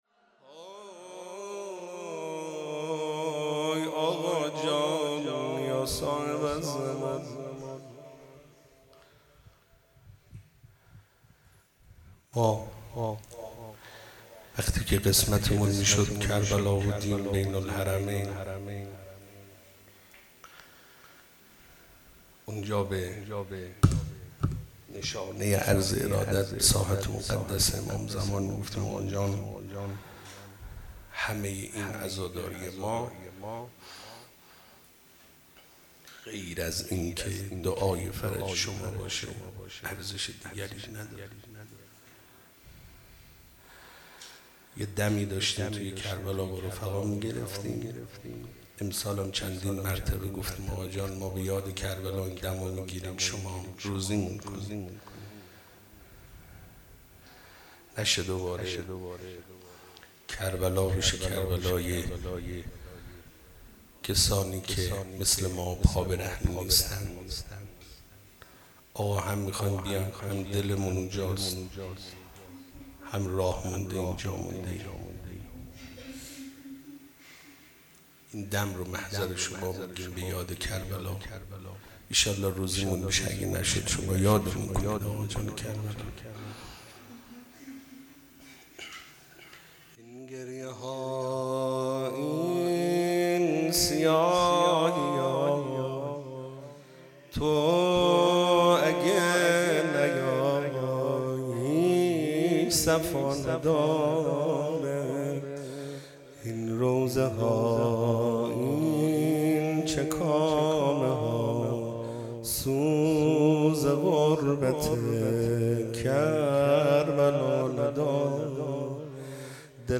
مناجات پایانی | این گریهها این سیاهیا | پنج شنبه ۲۵ شهریور ۱۴۰۰
جلسه‌ هفتگی | شهادت امام حسن مجتبی(ع) | پنج شنبه ۲۵ شهریور ۱۴۰۰